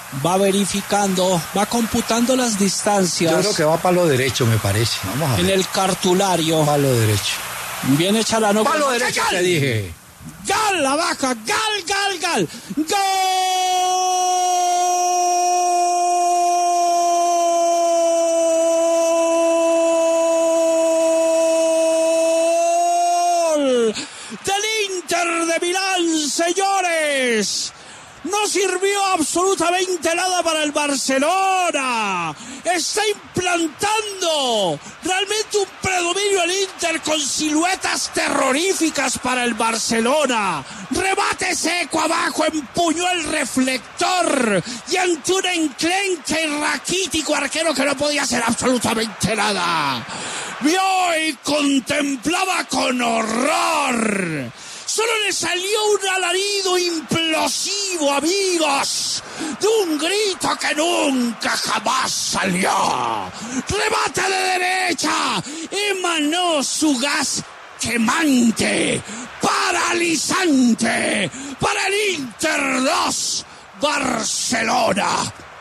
“Emanó su gas paralizante”: Así narró Martín de Francisco el gol de penal del Inter
Así fue la narración de Martín de Francisco del gol del Inter: